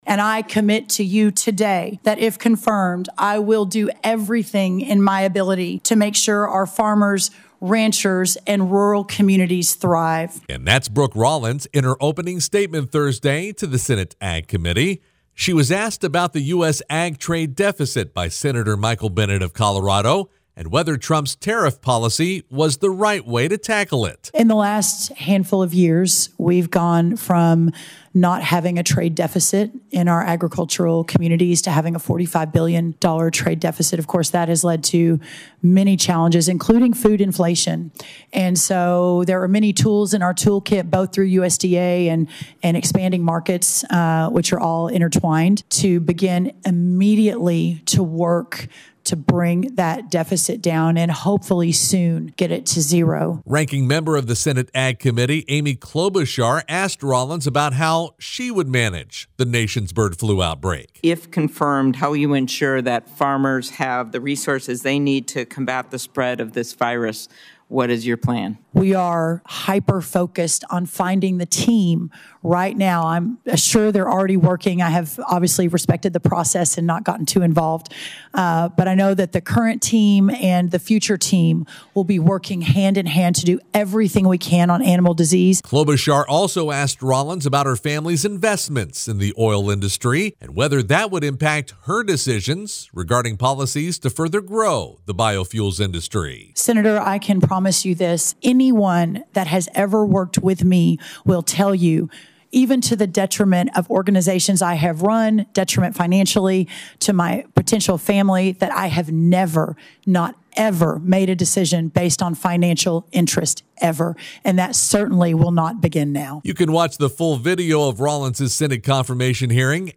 Bird flu, biofuels, and the ag trade deficit were among several topics discussed Thursday during Brooke Rollins’ Senate confirmation hearing for U.S. Secretary of Agriculture.